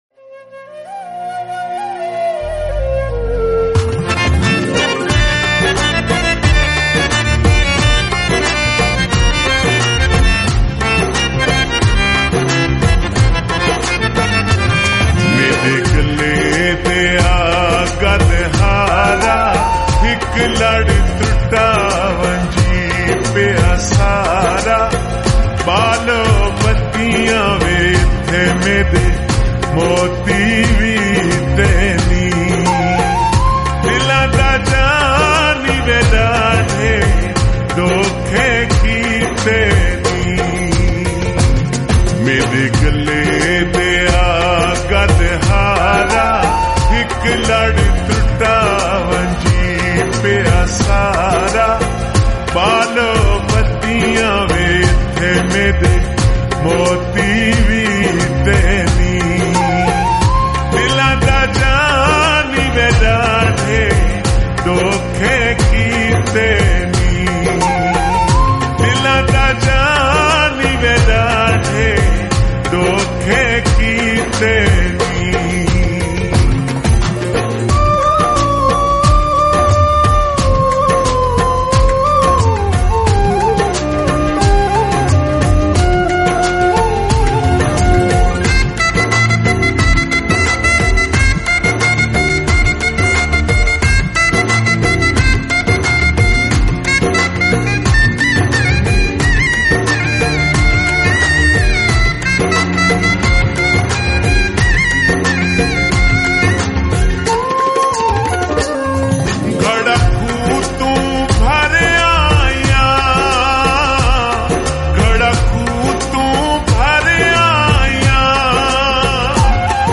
𝐒𝐚𝐫𝐚𝐢𝐤𝐢 𝐬𝐨𝐧𝐠
𝐏𝐮𝐧𝐣𝐚𝐛𝐢 𝐬𝐨𝐧𝐠